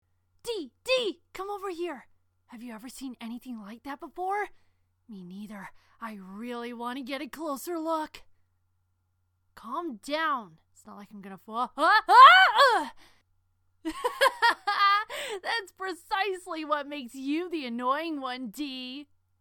Tweedleum is a very cheerful and bubbly character
Voice Example:
Tweedledum_audition.mp3